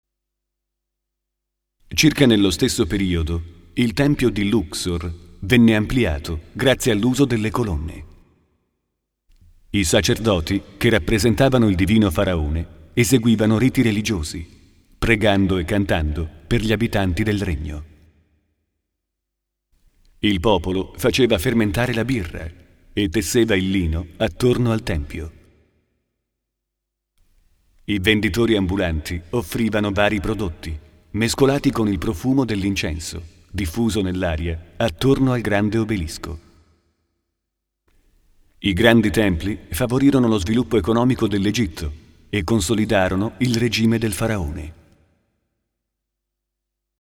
Kein Dialekt
Sprechprobe: Sonstiges (Muttersprache):
Speaker radio-tv-multimedia Soundesign - editing audio